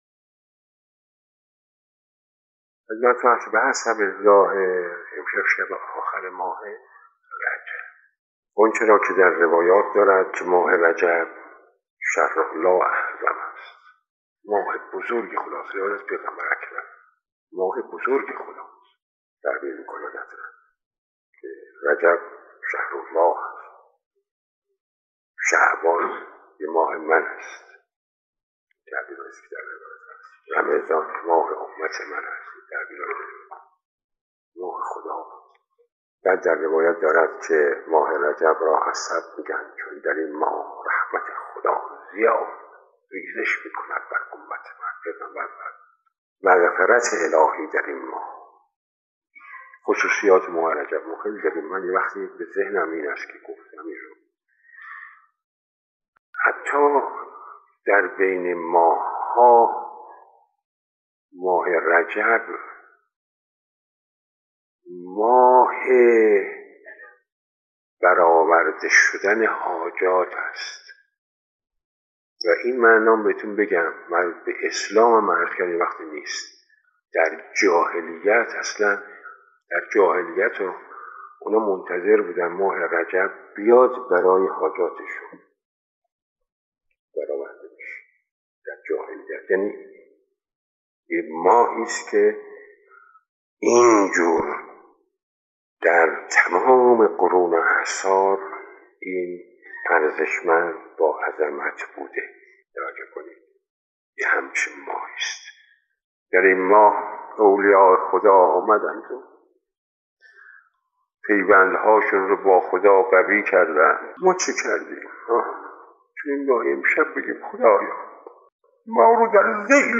به گزارش پایگاه خبری 598، مرحوم آیت الله آقا مجتبی تهرانی در یکی از جلسات اخلاق خود توصیه هایی به مناسبت روزهای آخر ماه رجب کرده است که تقدیم شما فرهیختگان می شود.